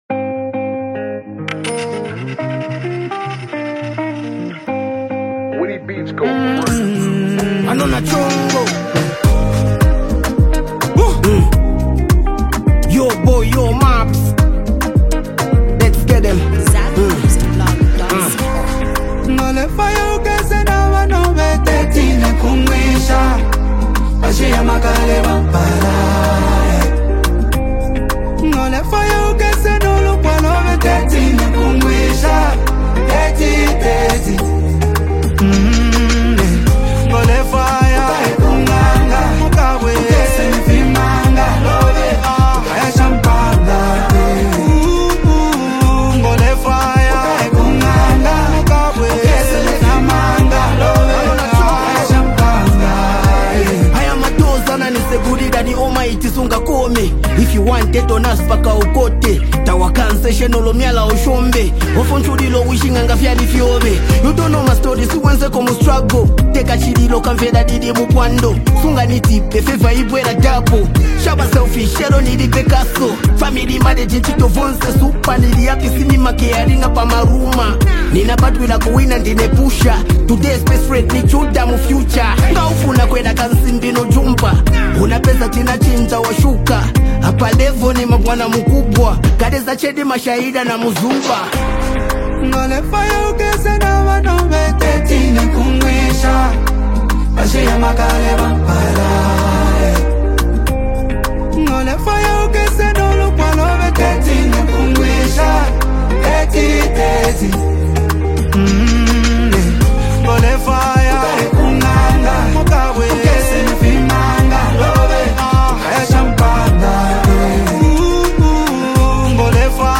Zambia’s highly gifted rapper and songwriter
uptempo gospel anthem